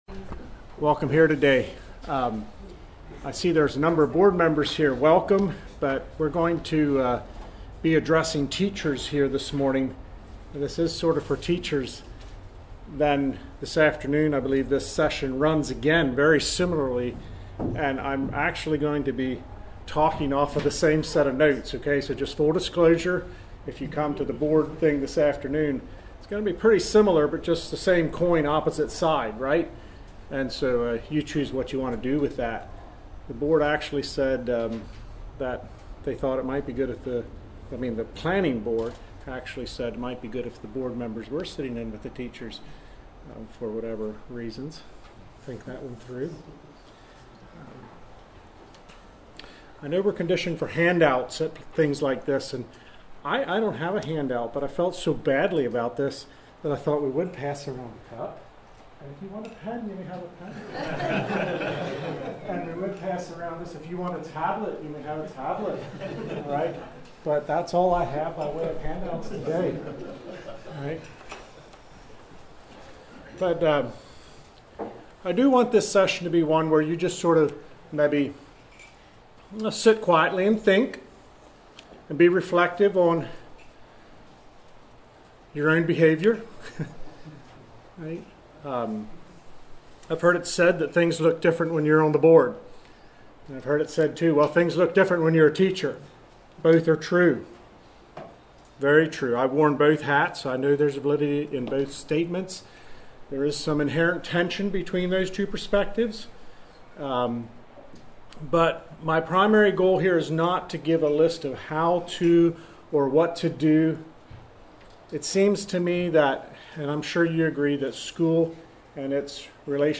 This workshop is for teachers who have actually outdistanced their managers, the board (or at least perceive this to be the case). If you are feeling smooshed or smothered, this discussion will offer some fresh air for maintaining mutual appreciation and respect throughout the team.